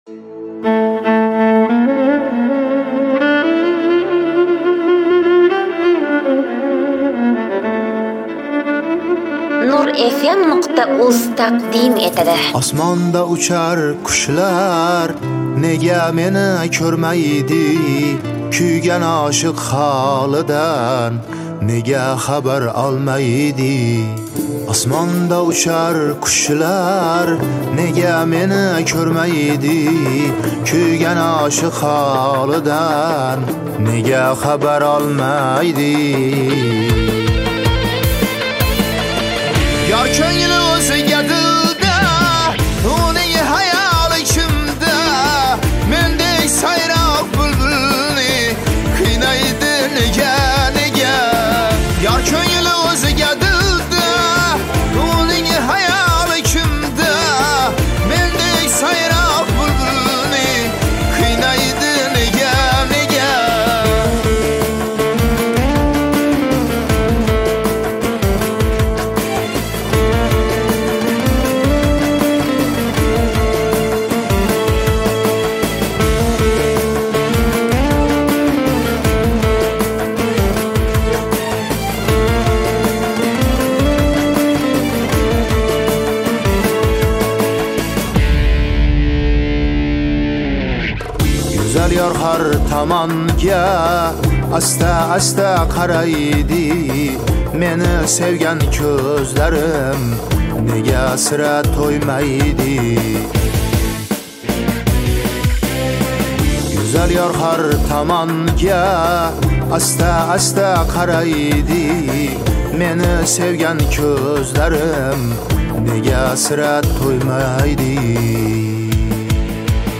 Хорезмская